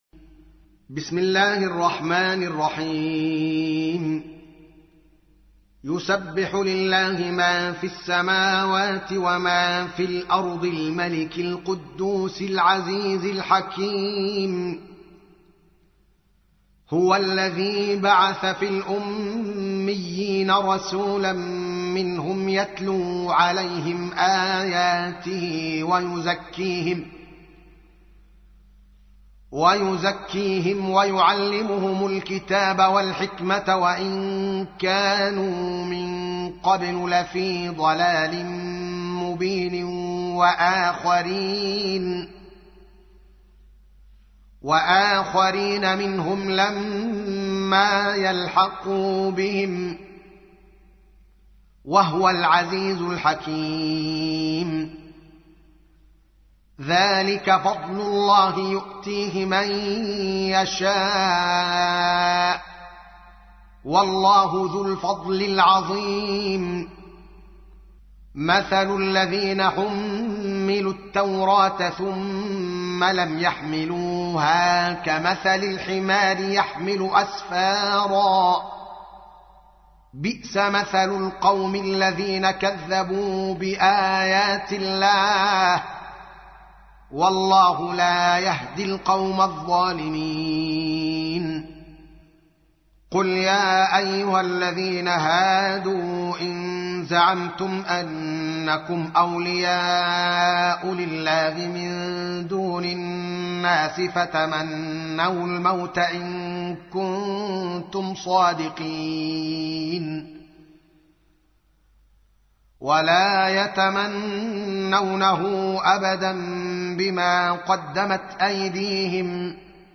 تحميل : 62. سورة الجمعة / القارئ الدوكالي محمد العالم / القرآن الكريم / موقع يا حسين